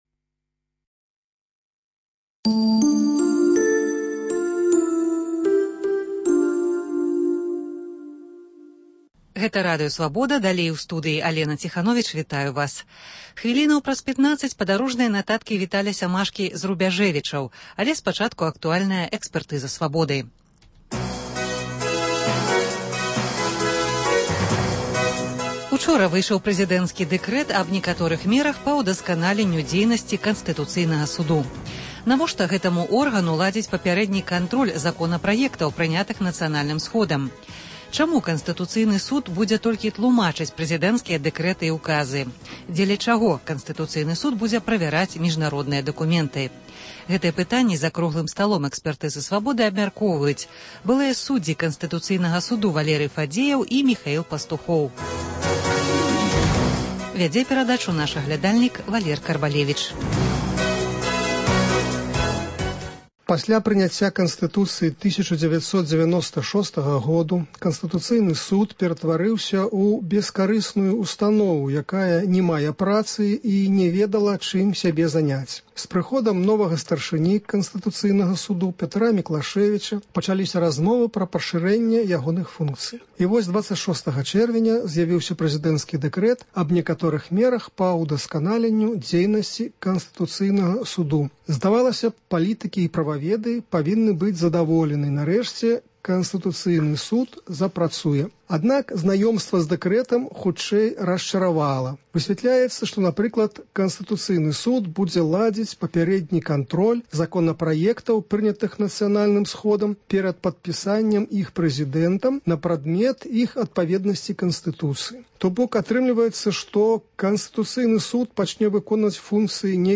Госьць у студыі адказвае на лісты, званкі, СМСпаведамленьні